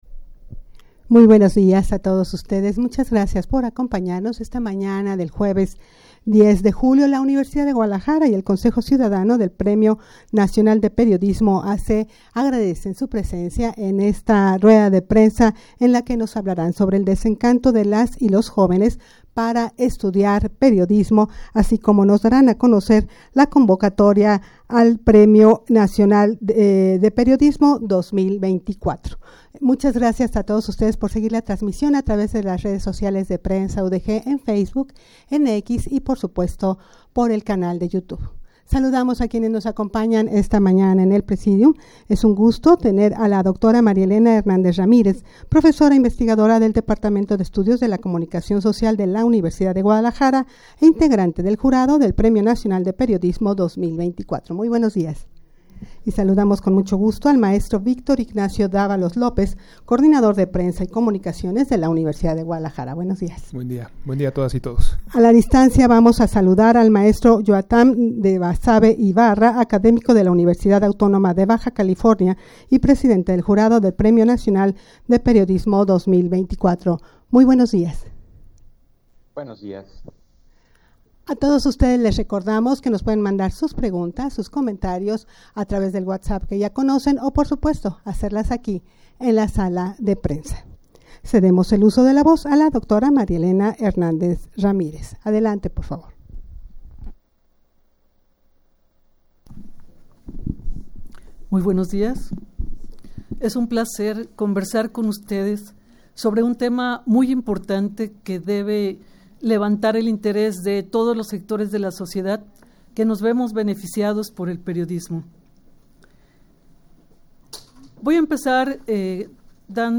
Audio de la Rueda de Prensa
rueda-de-prensa-desencanto-de-las-y-los-jovenes-para-estudiar-periodismo.mp3